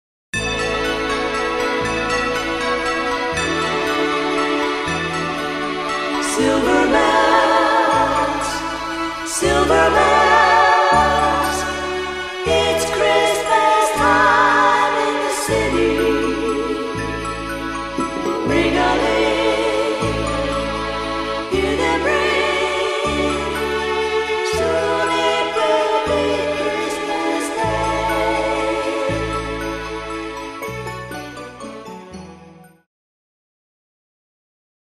Christmas Karaoke Soundtrack
Backing Track without Vocals for your optimal performance.